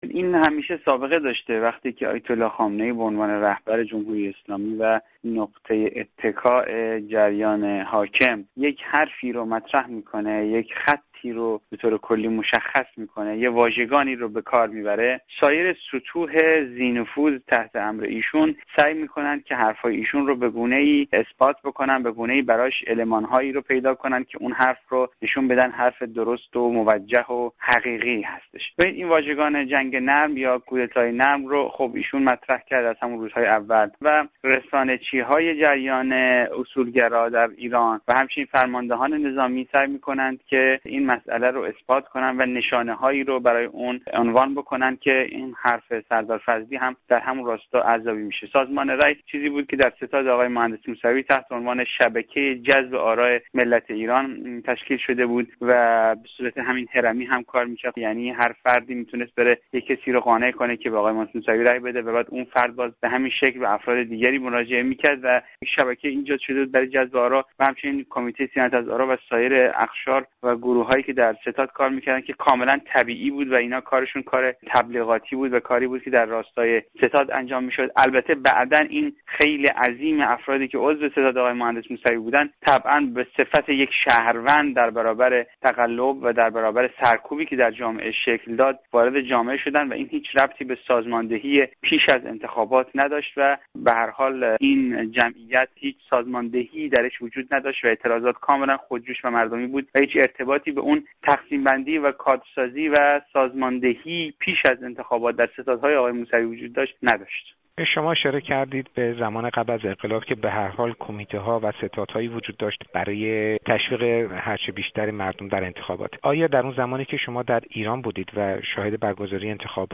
گفت‌گو